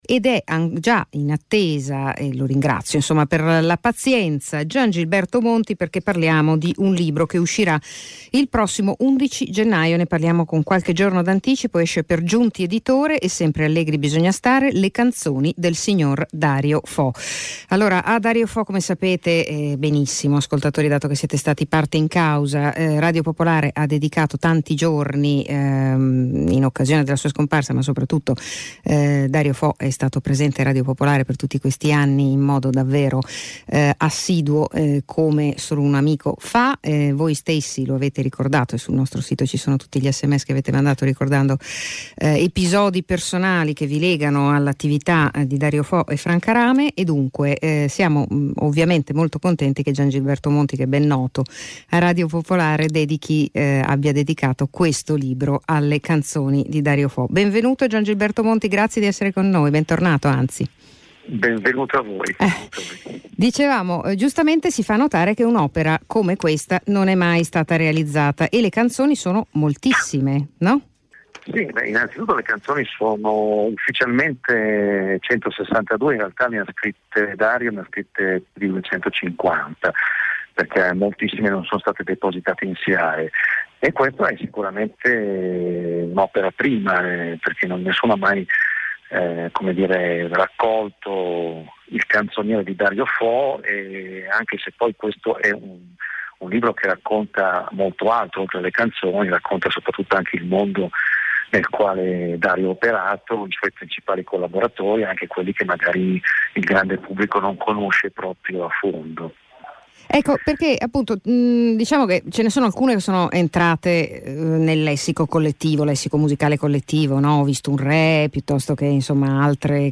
Ascolta l’intervista a Radio Lombardia